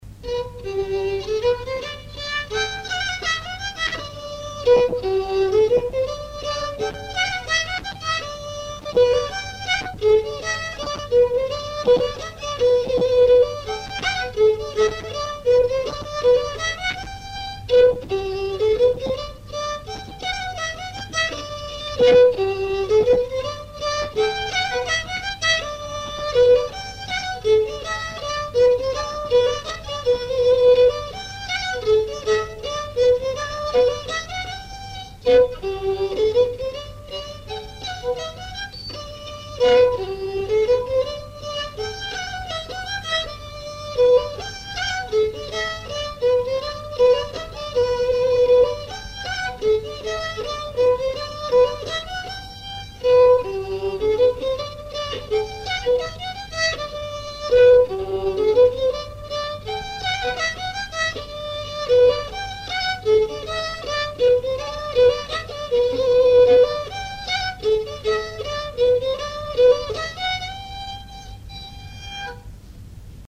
danse : scottich trois pas
enregistrements du Répertoire du violoneux
Pièce musicale inédite